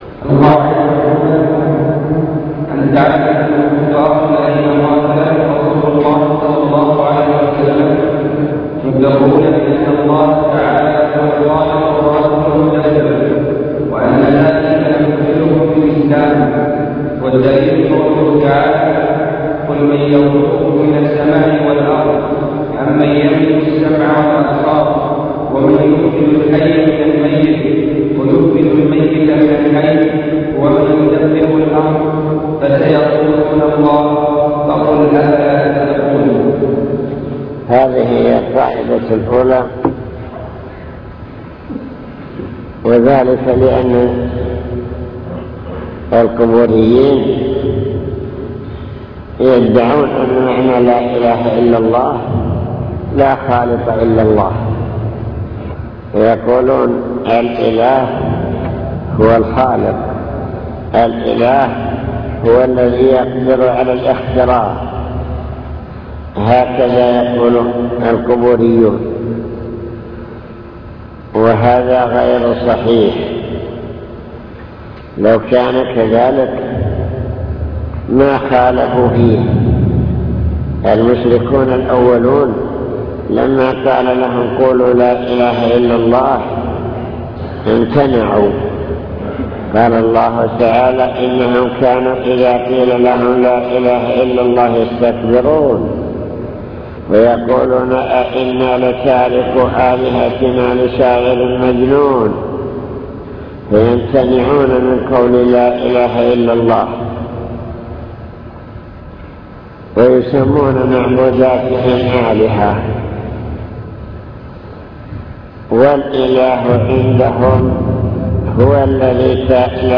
المكتبة الصوتية  تسجيلات - محاضرات ودروس  درس الفجر - القواعد الأربعة